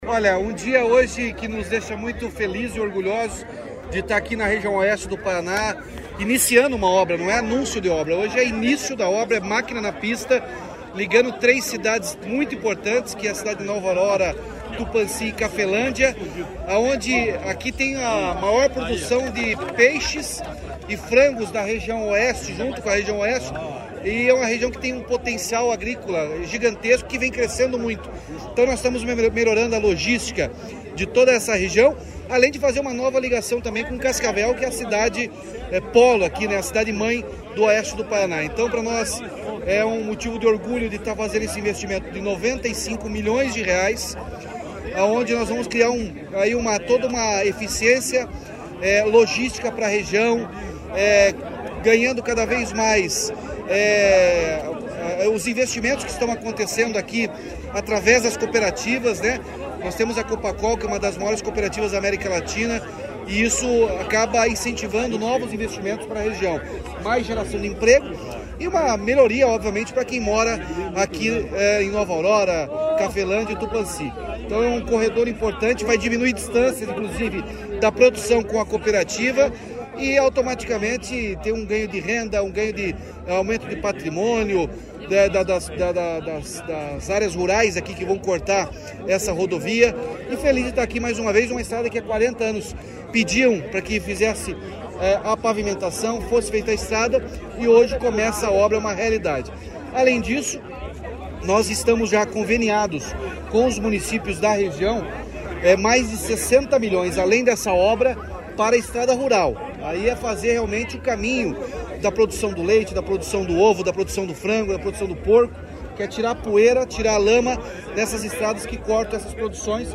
Sonora do governador Ratinho Junior sobre o início das obras nas PRs-574 e 575, na região Oeste do Estado